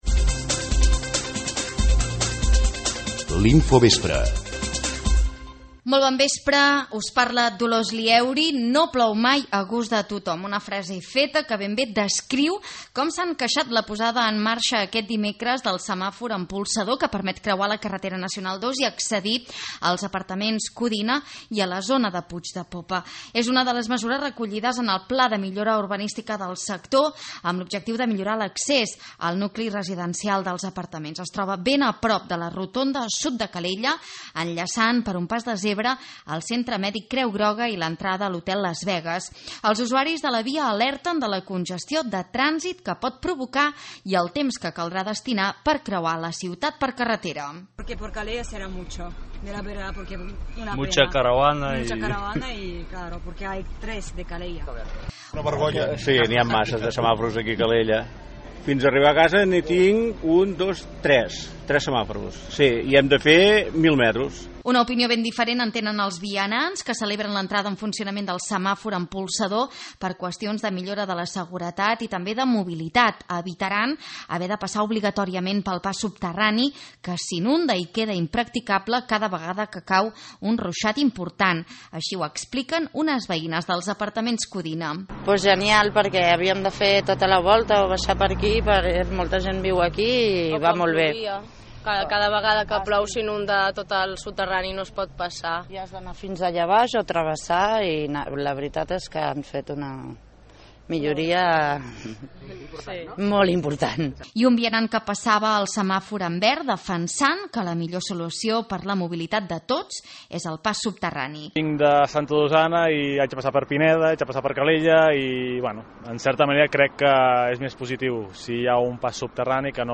En l’Info Vespre d’avui dimecres recollim les opinions de conductors i vianants sobre el nou semàfor amb polsador que aquest dimecres ha entrat en funcionament per millorar l’accés als apartaments Codina. Uns, els primers, alerten de la congestió de trànsit que pot provocar; els altres, destaquen els beneficis quant a la seguretat dels ciutadans que han de creuar la carretera N-II.